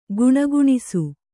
♪ guṇaguṇisu